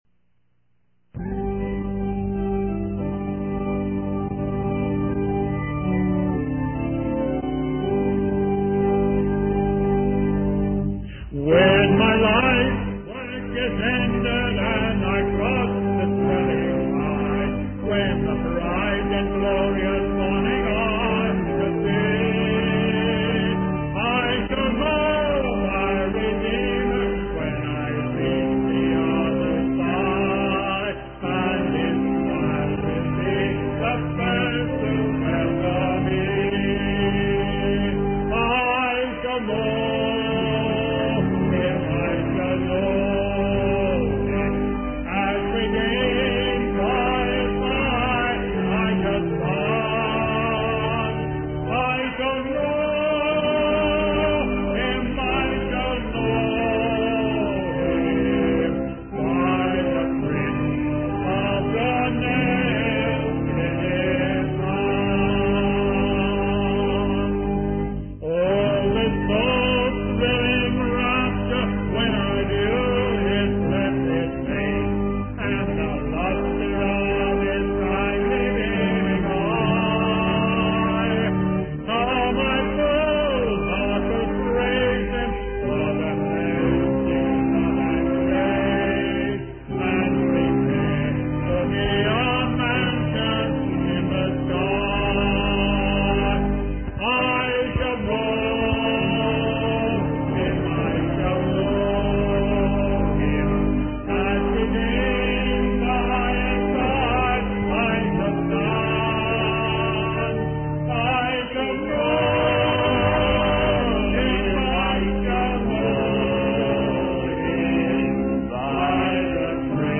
In this sermon, the speaker reflects on the uncertainty and questions that arise in life.